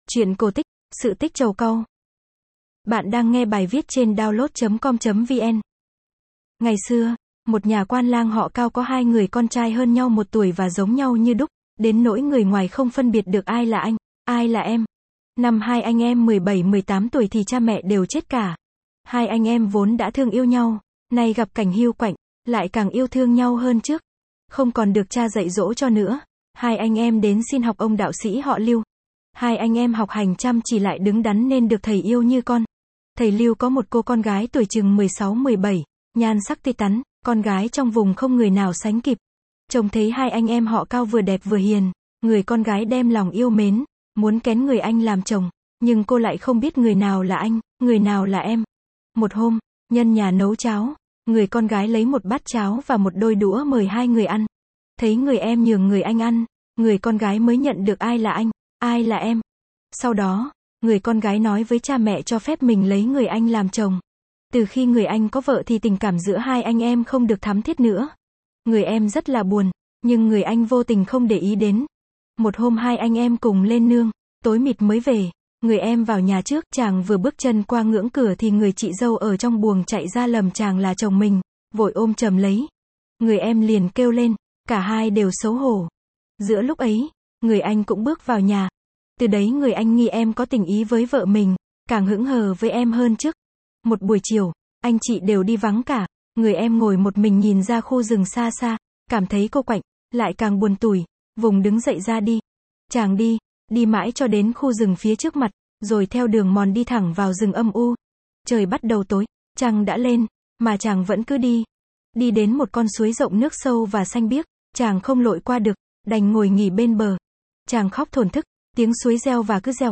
Sách nói | Sự tích trầu cau